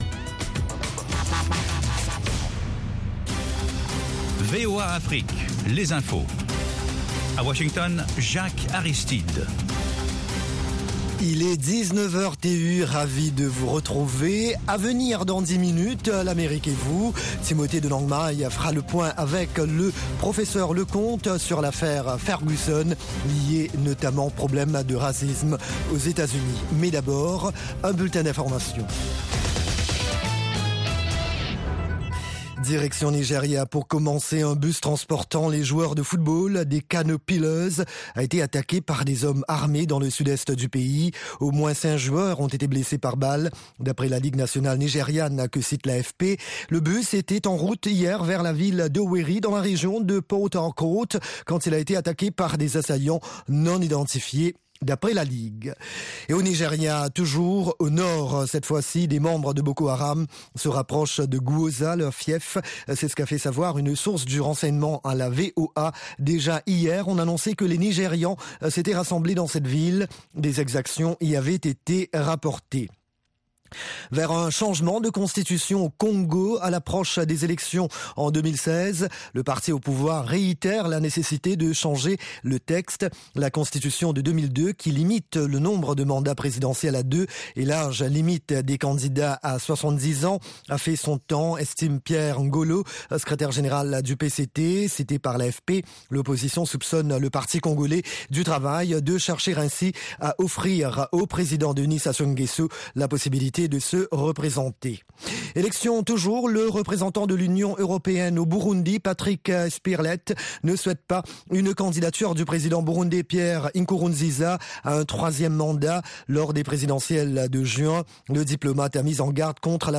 Bulletin
Newscast